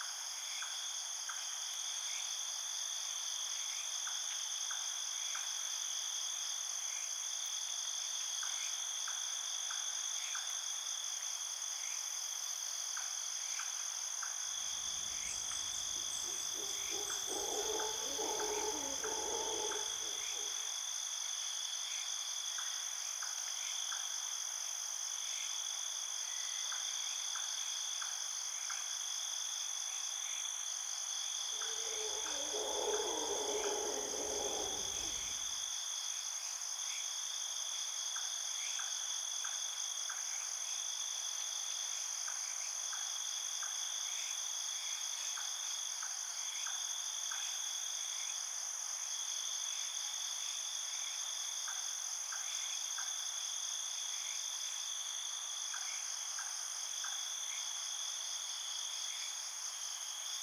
JungleAmbienceNight.ogg